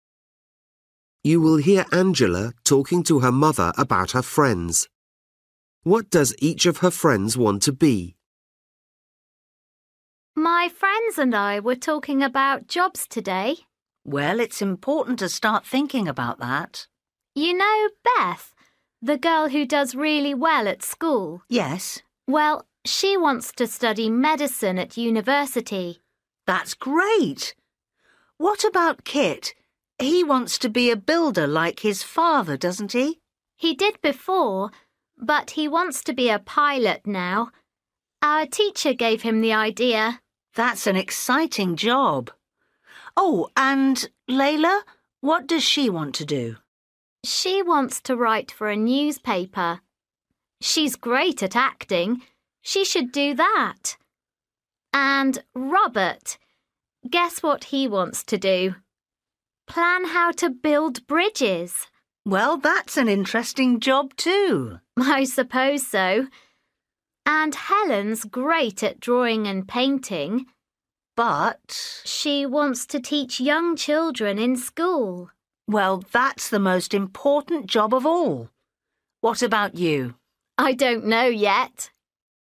You will hear Angela talking to her mother about her friends. What does each of her friends want to be?